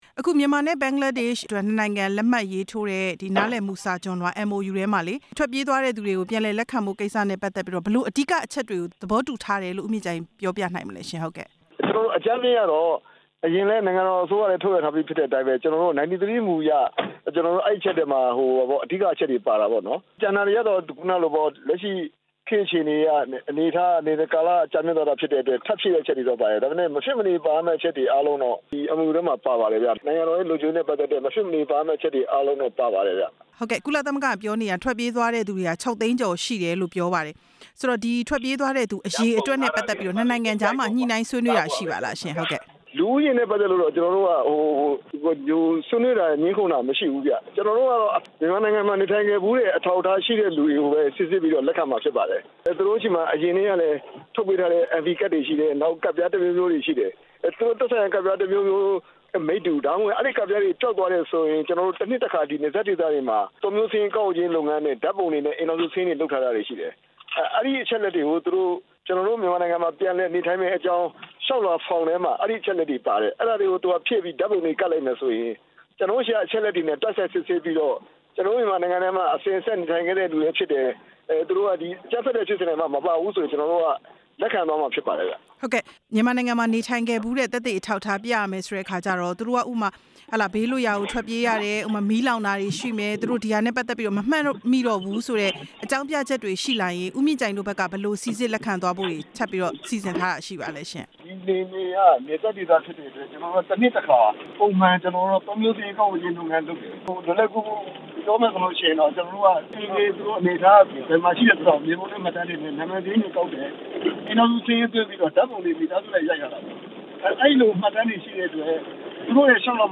ဒုက္ခသည်တွေ ပြန်လည်လက်ခံရေး အစီအစဉ်အကြောင်း ဆက်သွယ်မေးမြန်းချက်